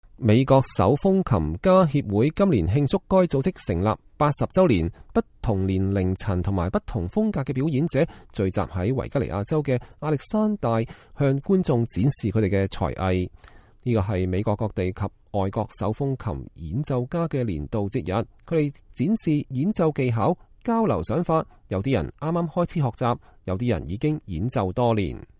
美國手風琴家協會今年慶祝該組織成立80週年，不同年齡層和不同風格的表演者們聚在維吉尼亞州的亞歷山德里亞，向觀眾展示他們的才藝。